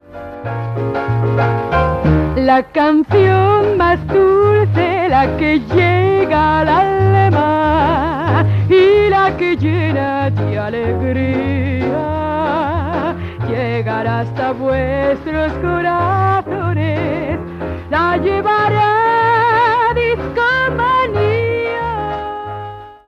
Sintonia cantada del programa
Musical